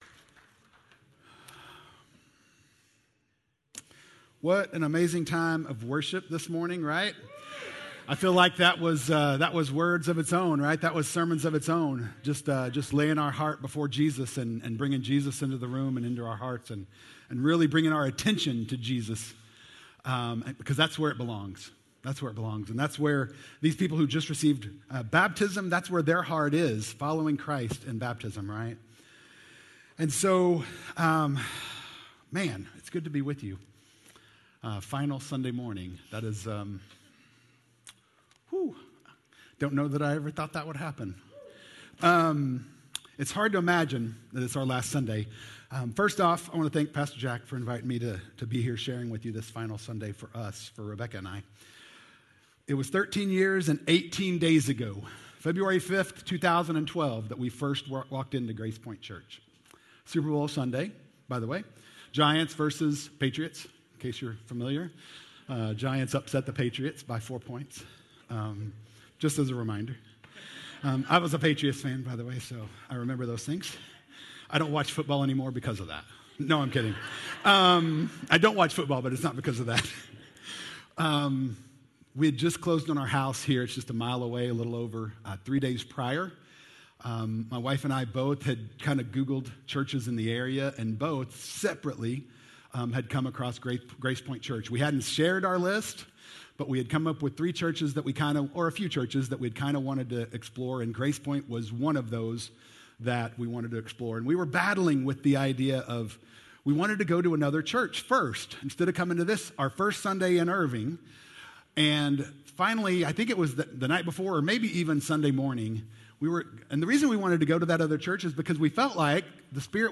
Sermons | Grace Pointe Church